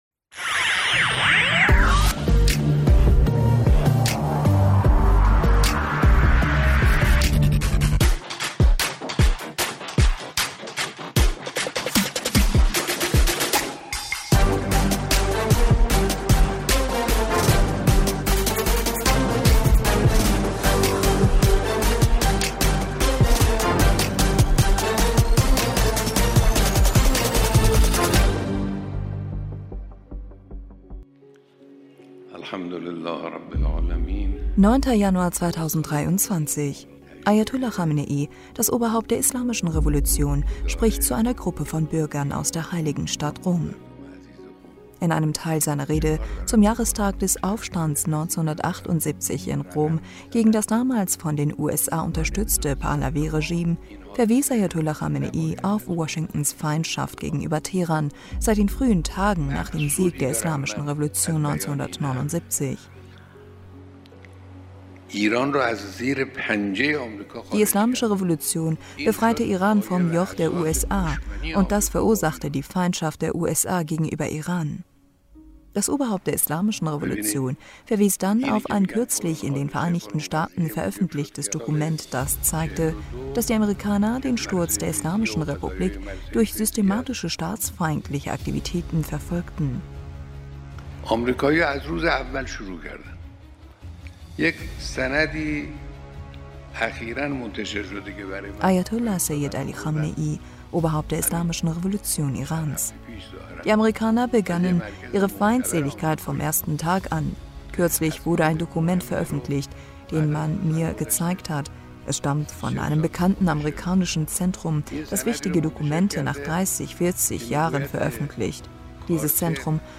9. Januar 2023 – Ayatollah Khamenei, das Oberhaupt der Islamischen Revolution, spricht zu einer Gruppe von Bürgern aus der heiligen Stadt Qom und verweist...